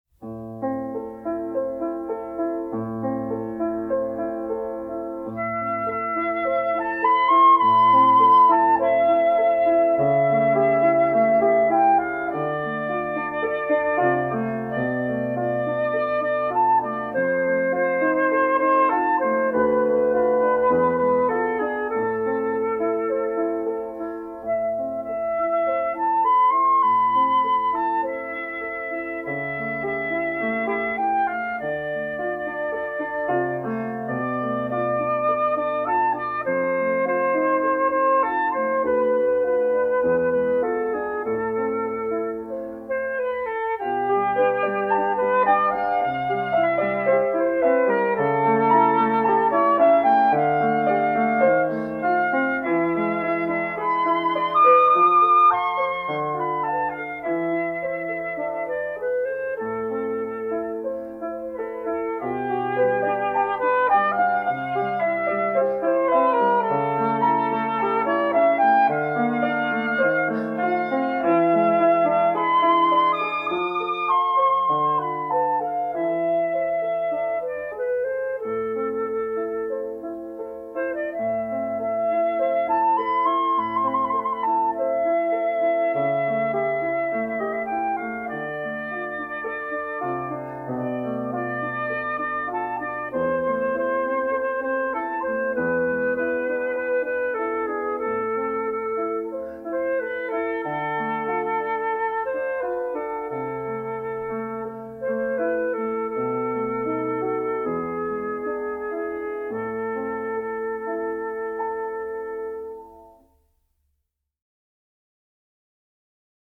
Романс
flute
piano